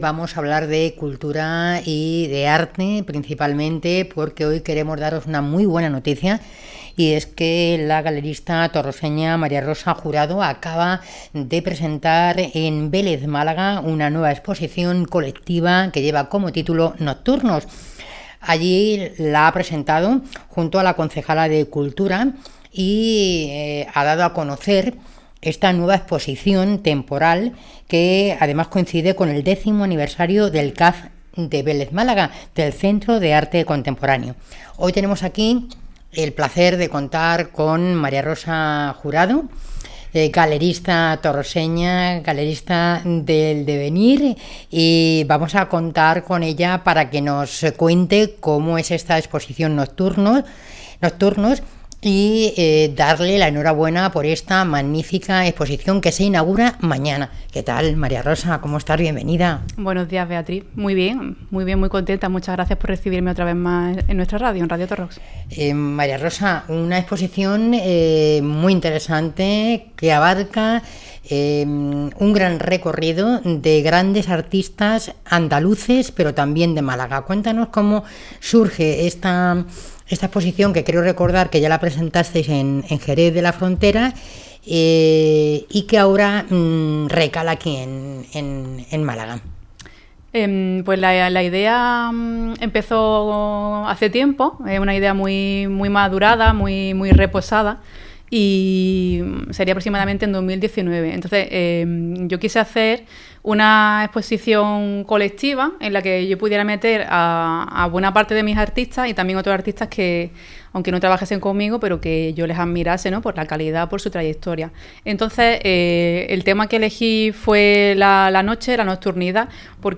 Entrevista en el programa Las Mañanas, Radio Torrox (05/04/24)